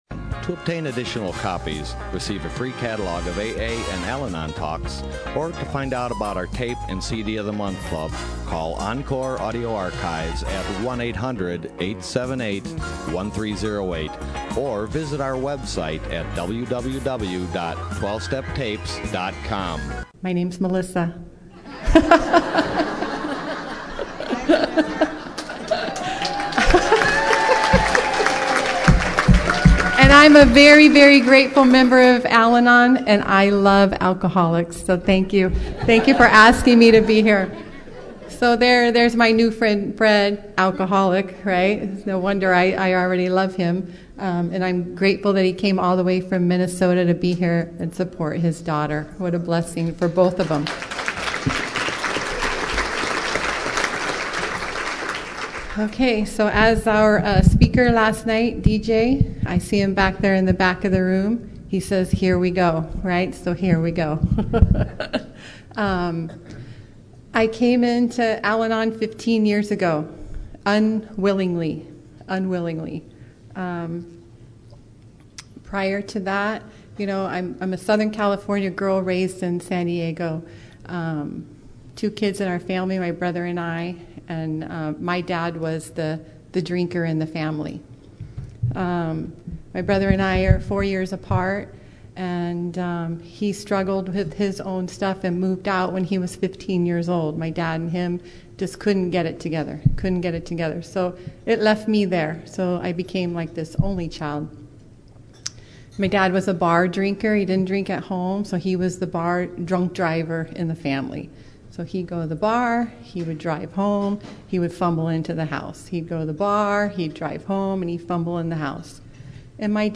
Orange County AA Convention 2014
AFG LUNCHEON &#8211